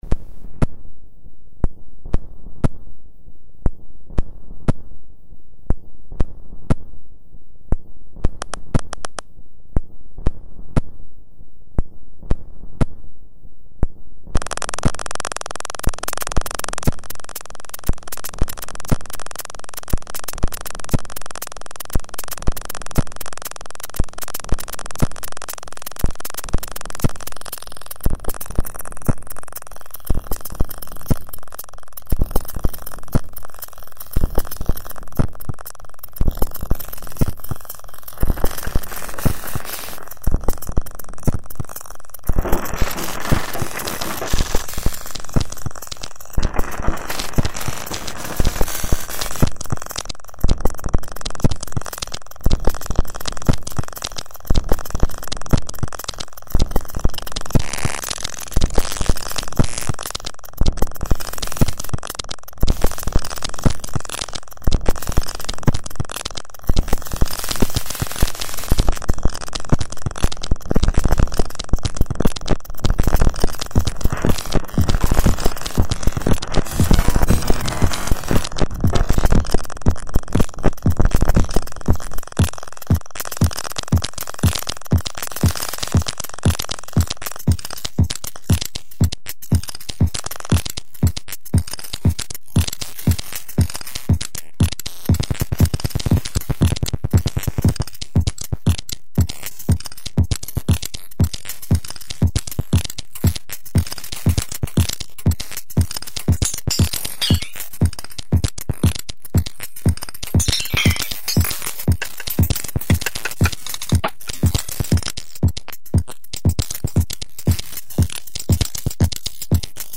File under: Rhythmic Gravel Propulsion